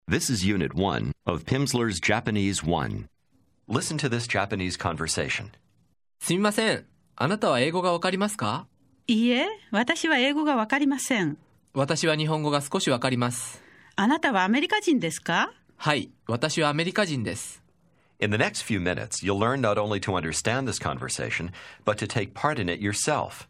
Аудио курс для самостоятельного изучения японского языка.